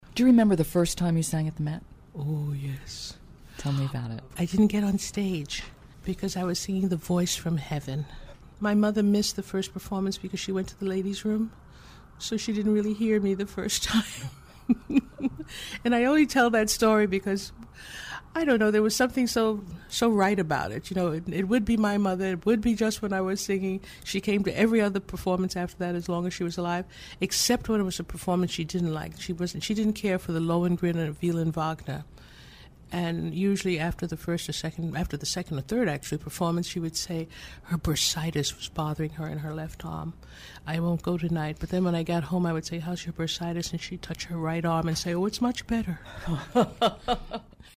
Transcript of conversation with Martina Arroyo